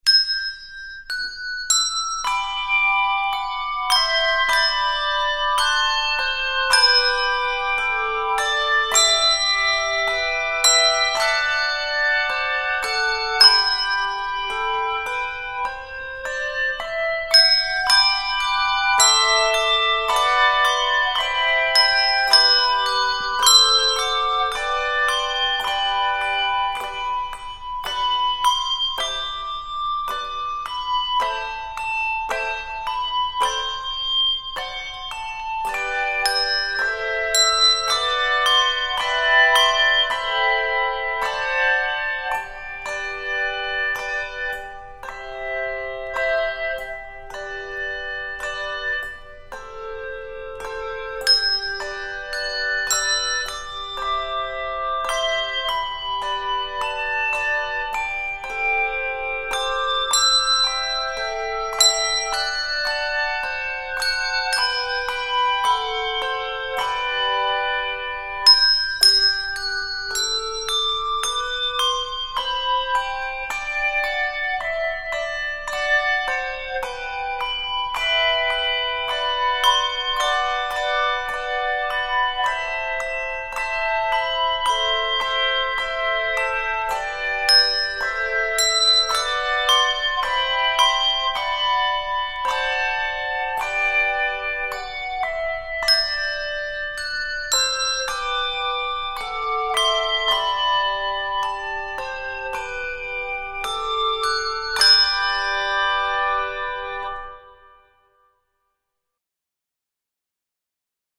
stately arrangement